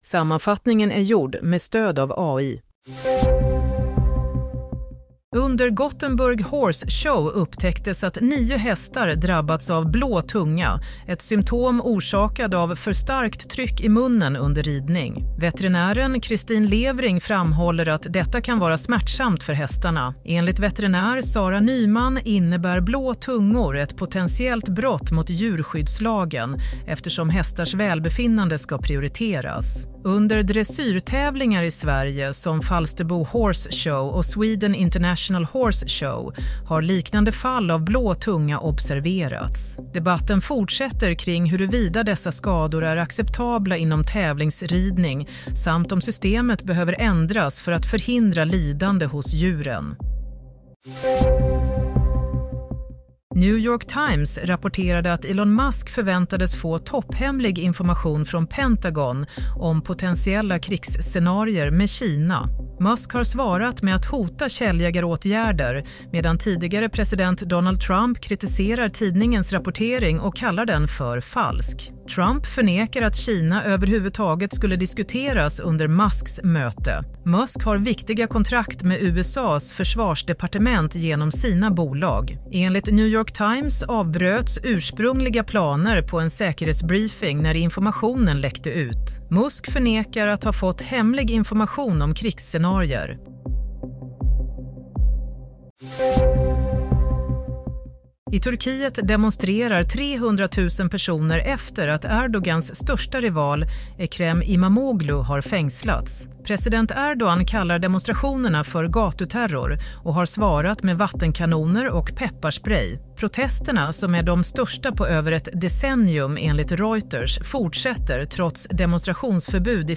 Play - Nyhetssammanfattning 22 mars 07.00